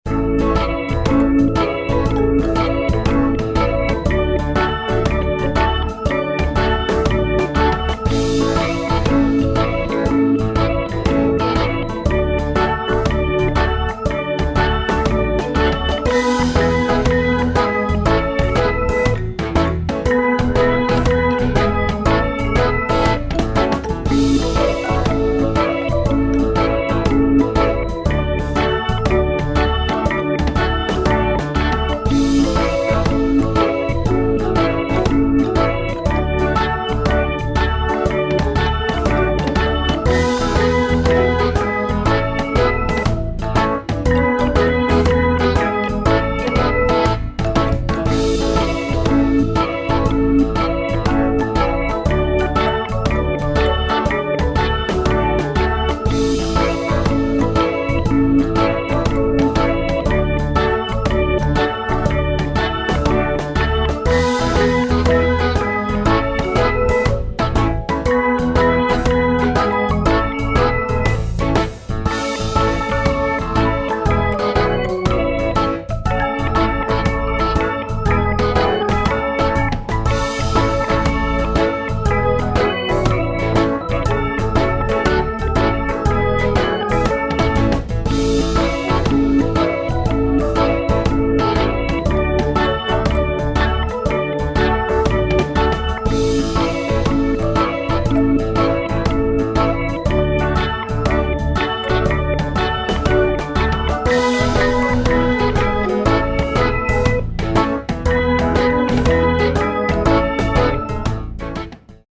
midi-demo 1